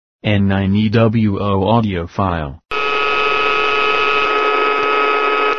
There is one little bug-a-boo that involves the keys "Beep" feedback sound. This appears in the receivers sub audio as intermittent groans and clicks.
Listen to our MP3 audio file as recorded from the test sample here (NOTE : I has been slightly edited with increased volume, it's not quite this loud).
frg100_audio_noise.mp3